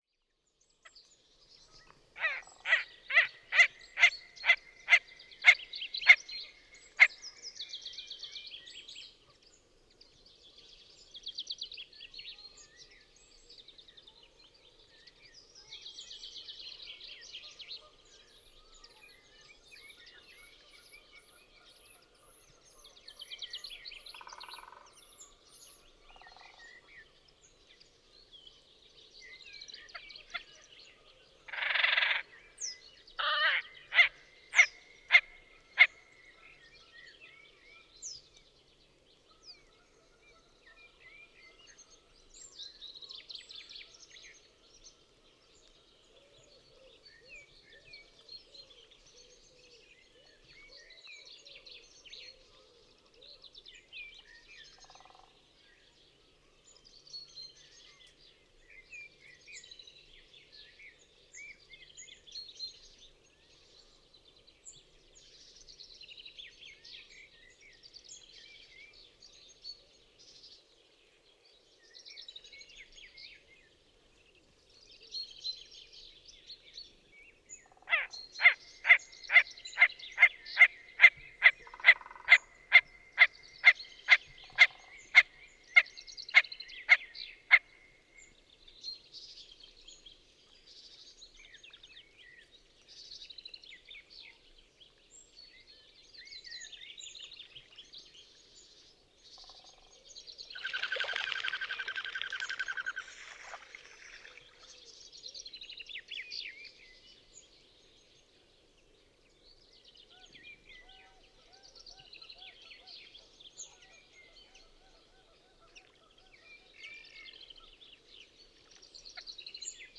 Mergullón cristado
Podiceps cristatus
Canto